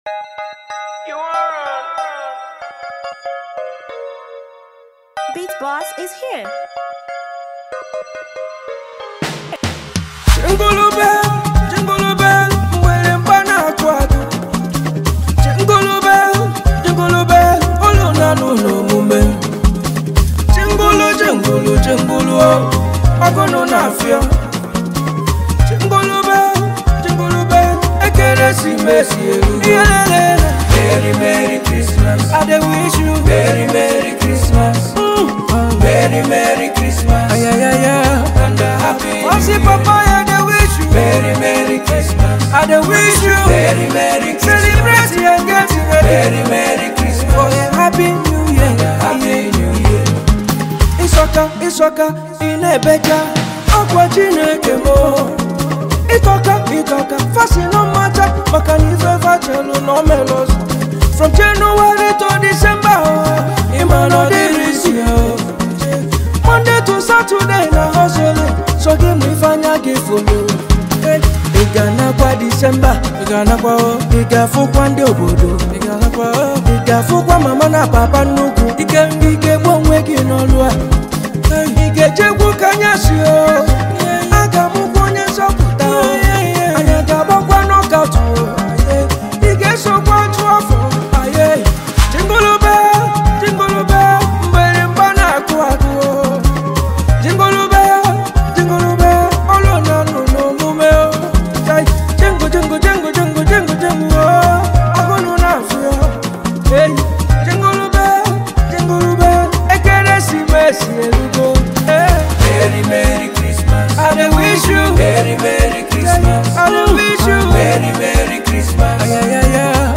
Veteran Nigerian highlife maestro
a festive, culturally rich, and celebratory song